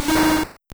Cri de Scorplane dans Pokémon Or et Argent.